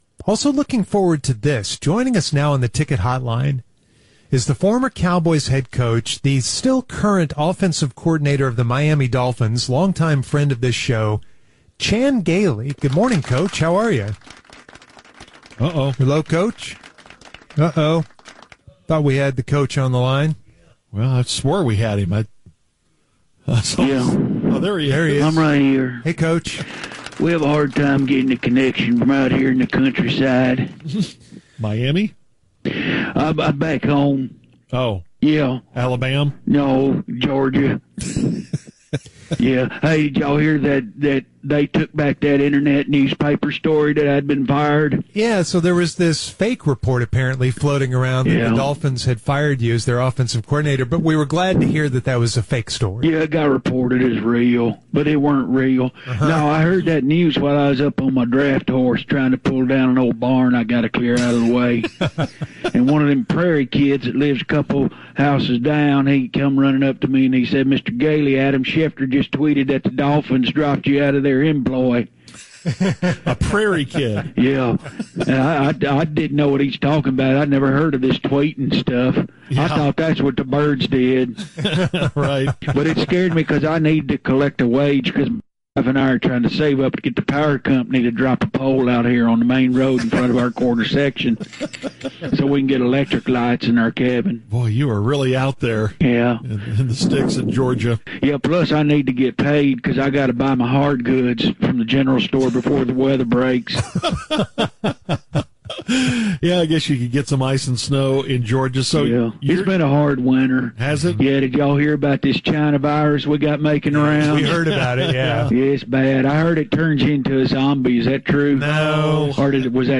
Coach finds a phone connection to address the premature report of the Dolphins dropping him from their employ.